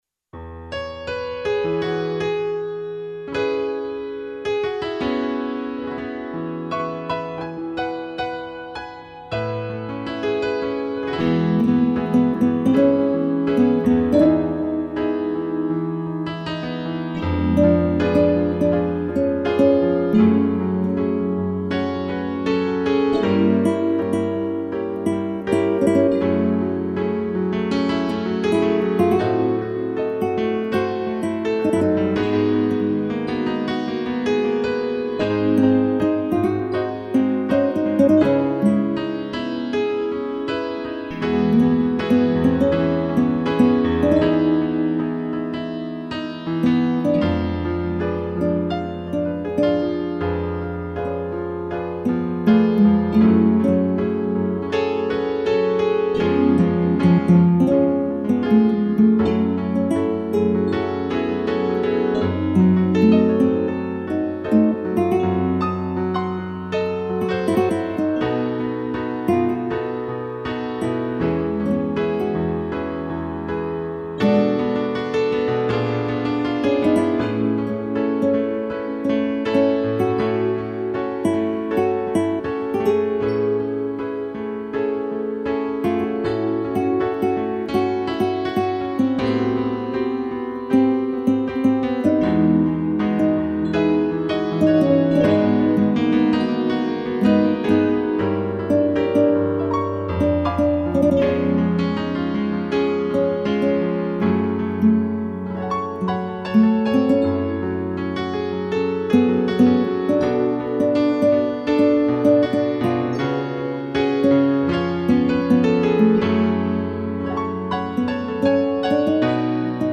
2 pianos
solo violão
(instrumental)